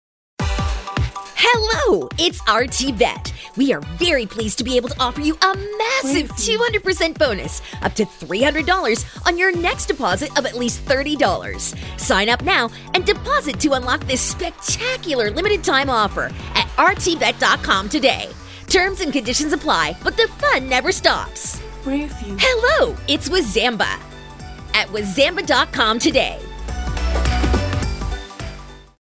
廣告-熱情活力